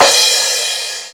• Huge Crash Cymbal Sample F Key 12.wav
Royality free crash cymbal drum sample tuned to the F note. Loudest frequency: 4325Hz
huge-crash-cymbal-sample-f-key-12-izw.wav